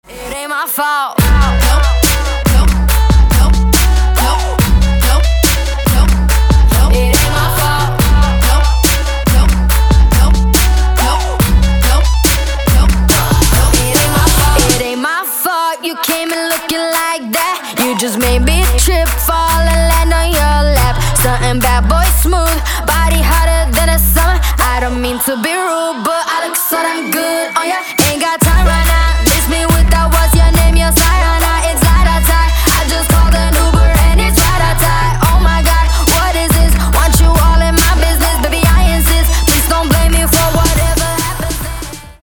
• Качество: 192, Stereo
поп
женский вокал
dance
Electronic
басы
vocal
сабвуфер